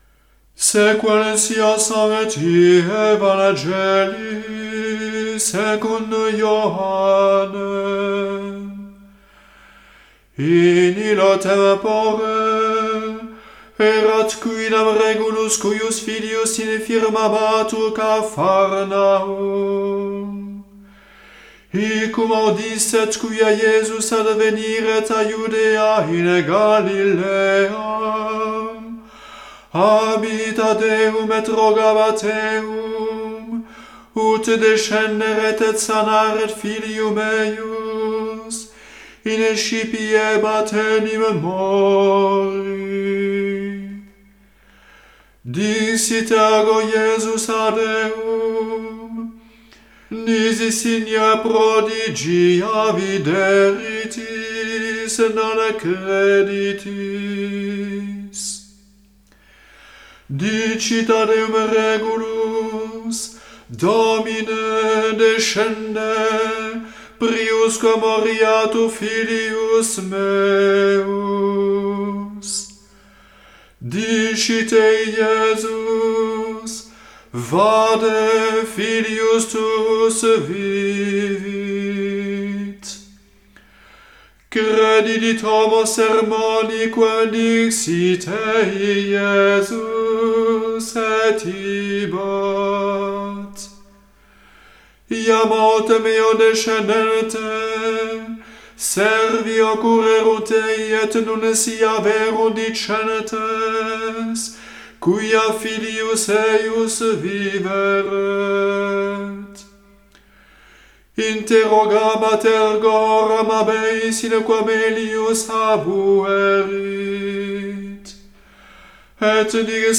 Evangelium
Evangile du XXe dimanche apr�s la Pentec�te NB.